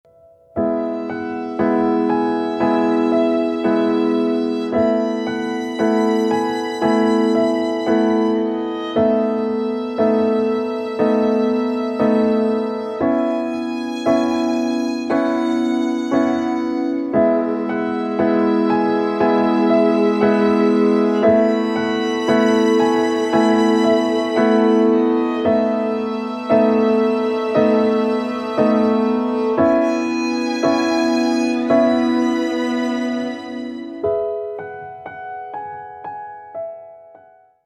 красивые
спокойные
без слов
скрипка
инструментальные
пианино